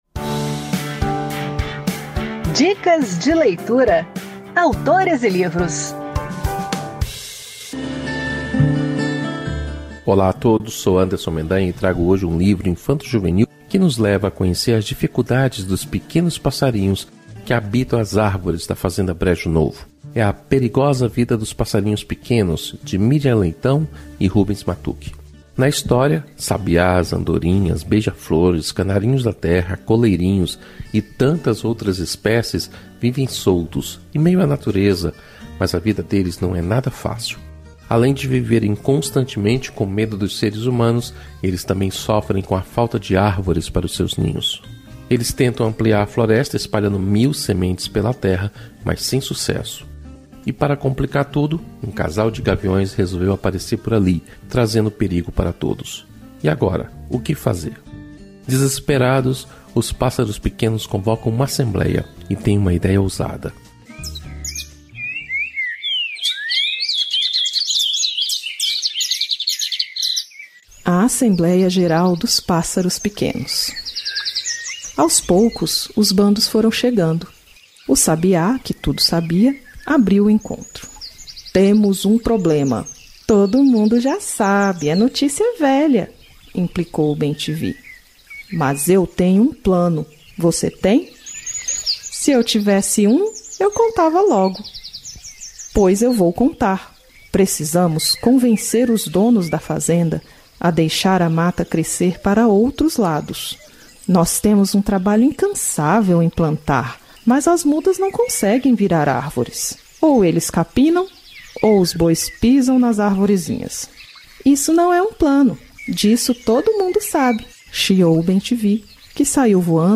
Acompanhe a dica de leitura com o jornalista